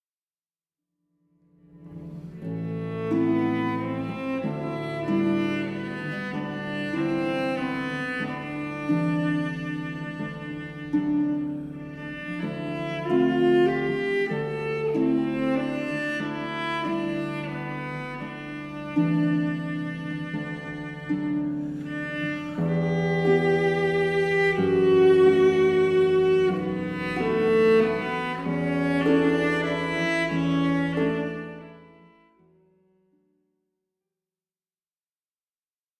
Lent et douloureux (ré majeur) (0.99 EUR)